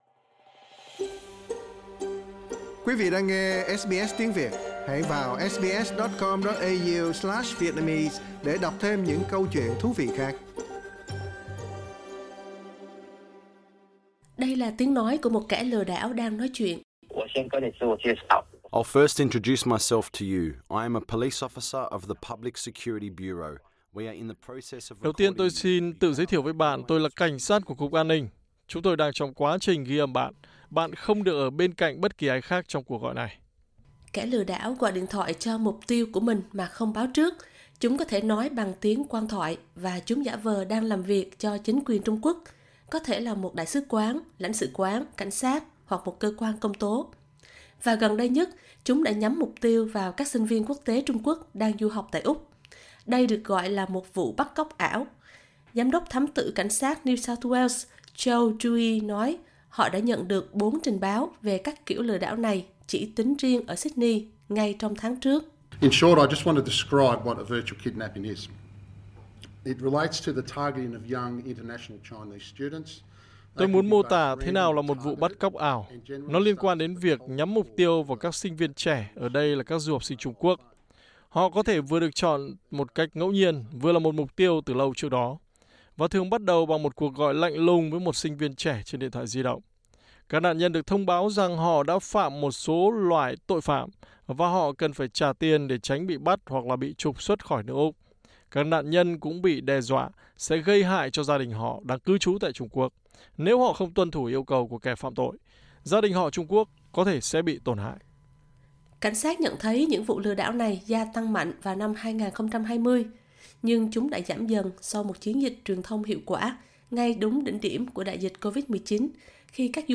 Đây là tiếng nói của một kẻ lừa đảo đang nói chuyện: Đầu tiên tôi xin tự giới thiệu với bạn, tôi là cảnh sát của Cục An ninh …Chúng tôi đang trong quá trình ghi âm bạn, bạn không được ở bên bất kỳ ai khác trong cuộc gọi này.